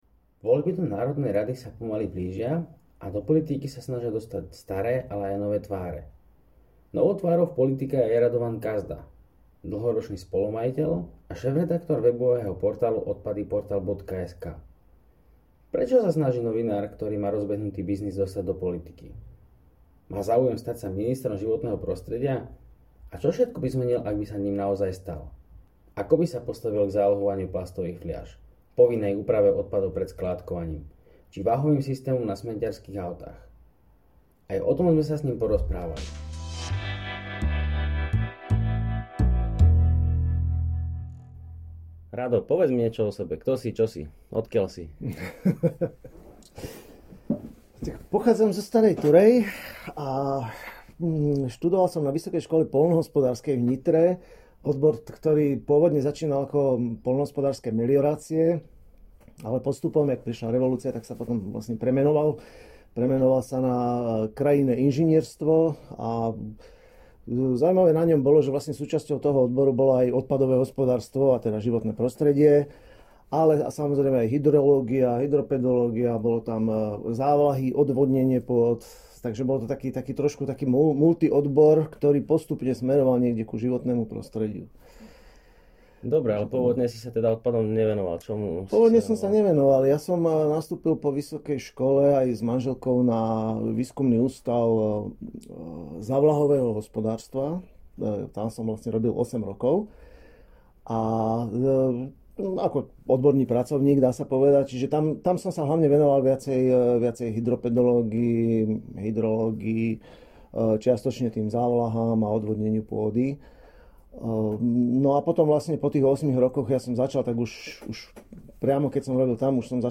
Prinášame rozhovor s ním